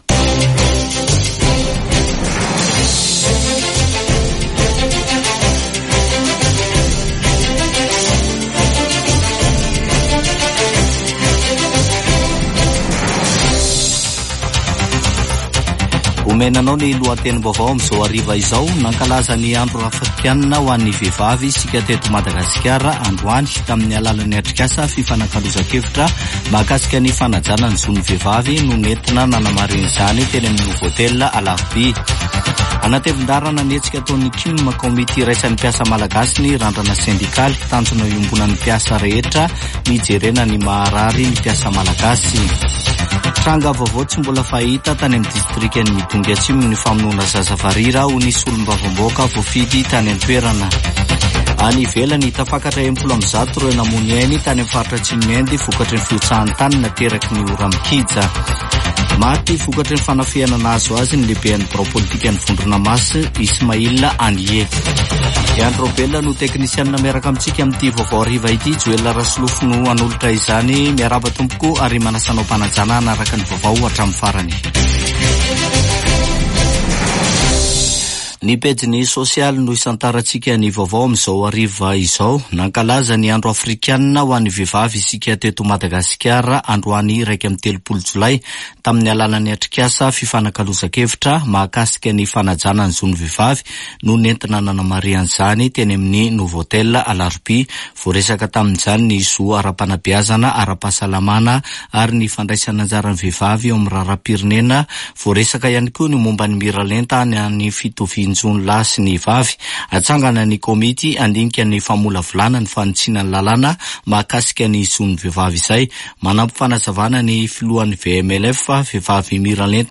[Vaovao hariva] Alarobia 31 jolay 2024